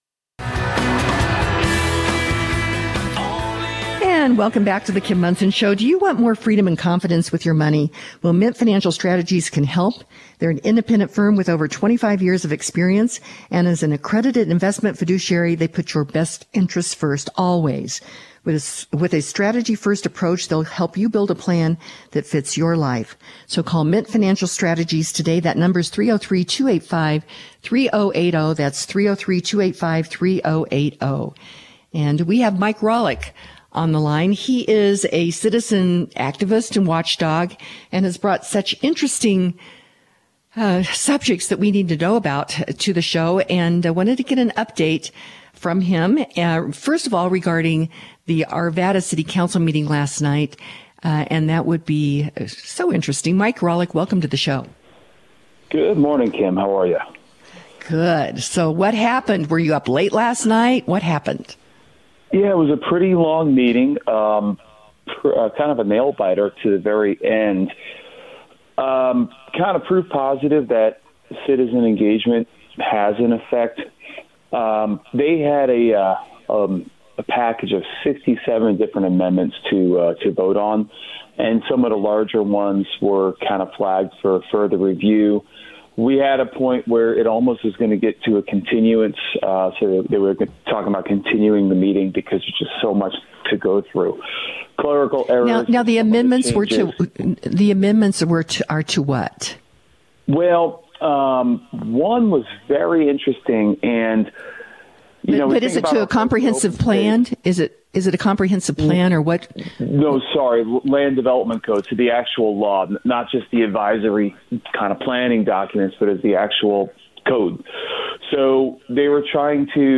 LDC follow up interview